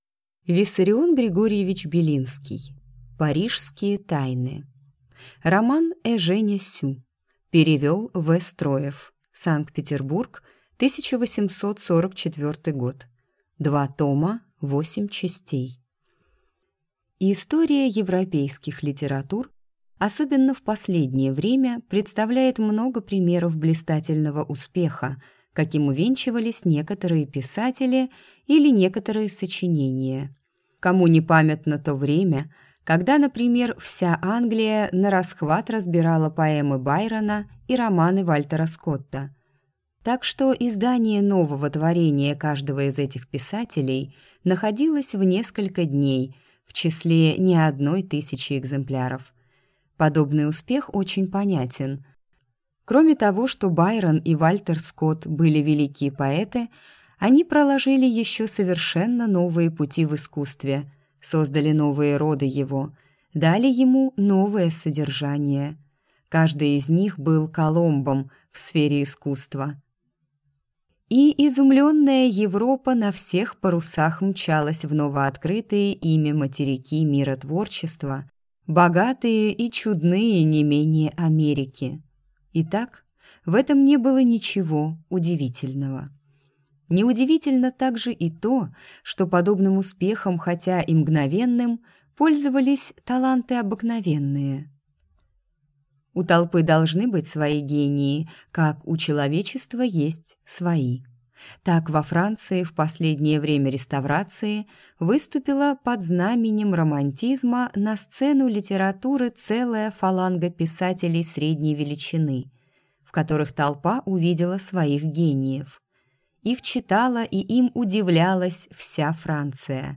Аудиокнига Парижские тайны | Библиотека аудиокниг